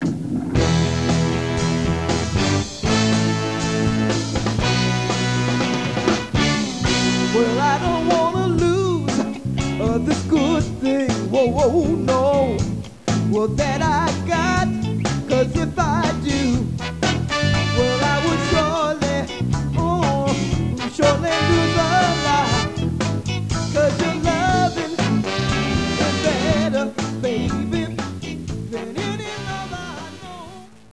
Lead Vocals